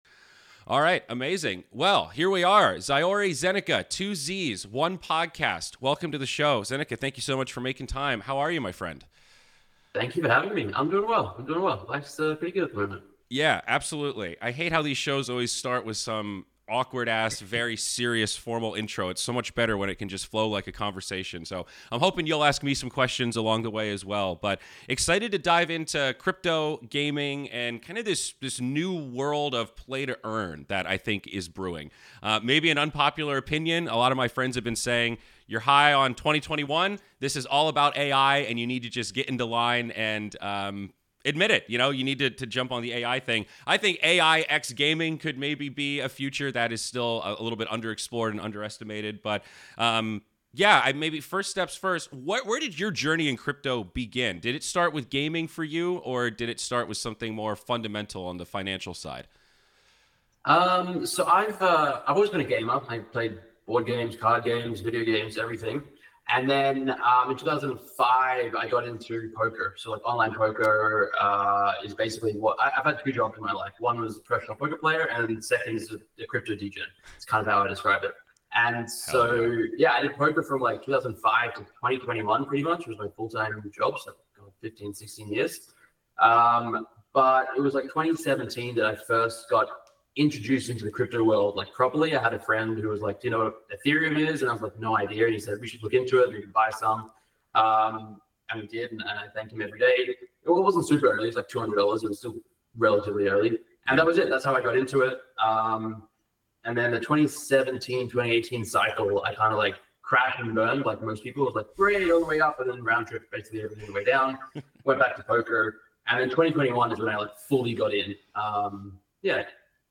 Candid, one-on-one discussion with some of the most interesting minds in esports, blockchain, and gaming.